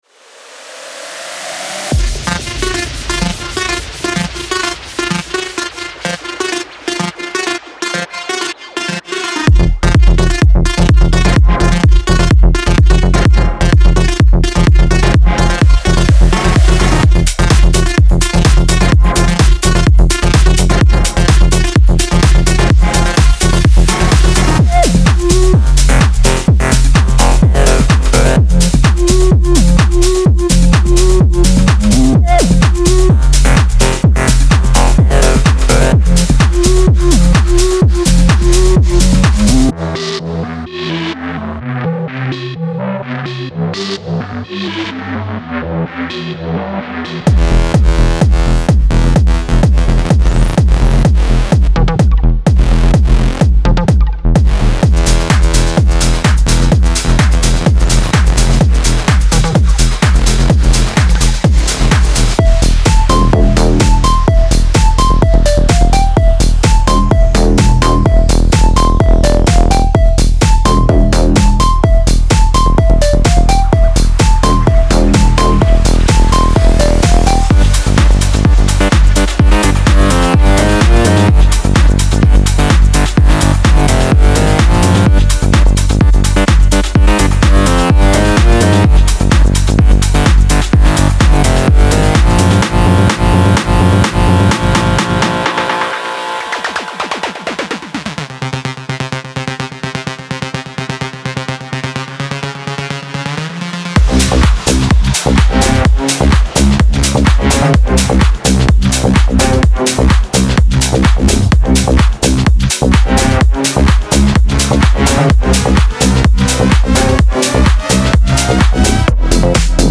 クラブアンセムの土台となる、サチュレーションの効いた力強いローエンドを収録しています。
フロアを惹きつけ続けるフックのあるリード、リズミックなスタブ、モジュレーションされたテクスチャを収録しています。
瞬時に個性を加える高揚感あふれるフレーズやリズミックなチョップを収録しています。
重厚なキックから鮮明なトップエンドパーカッションまで、精密に設計されたグルーヴを収録しています。
エネルギーの移行をコントロールするシネマティックなスウィープ、ライザー、フォールを収録しています。
デモサウンドはコチラ↓
Genre:Tech House
127 BPM